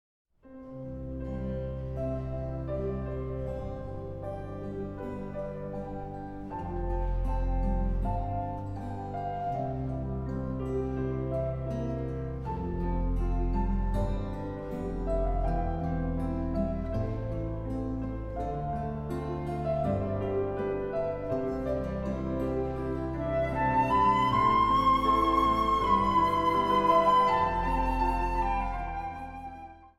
meisjessopraan
fluiten
gitaar & percussie
orgel
Zang | Solozang